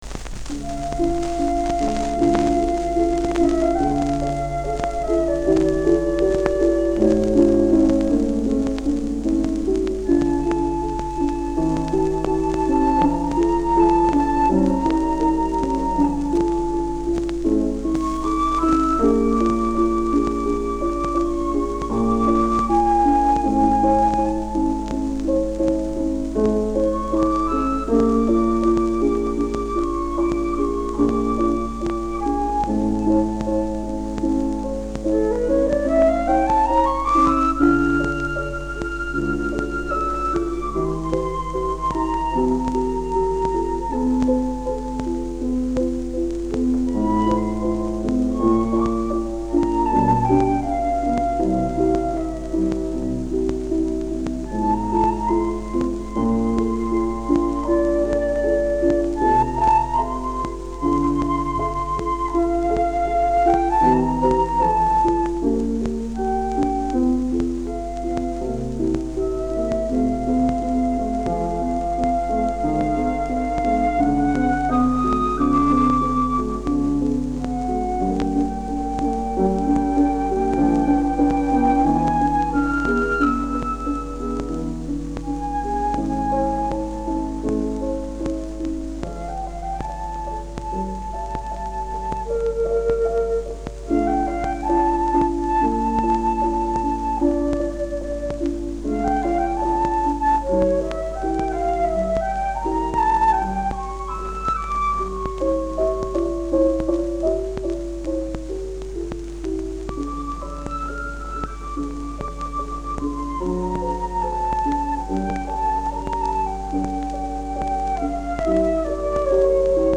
492-melodija-fleyt-sovs.mp3